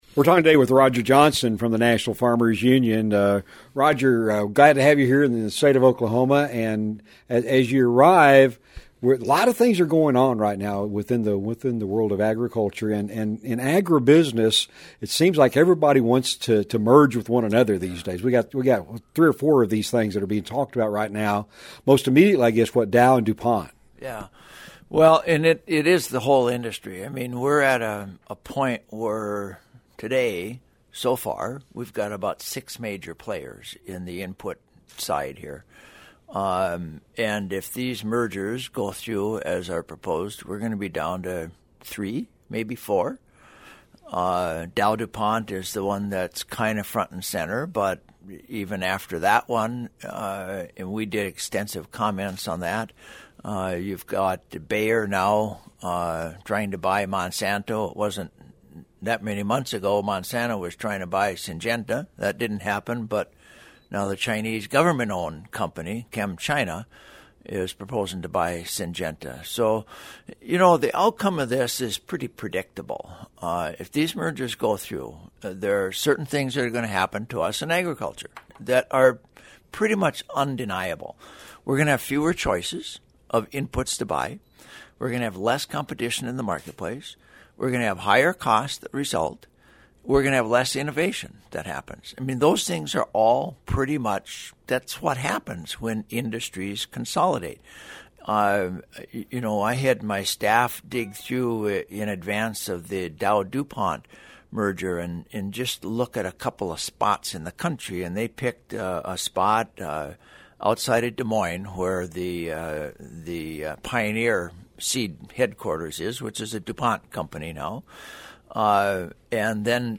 Click on the LISTEN BAR below to hear them talk more about mergers in the agriculture industry.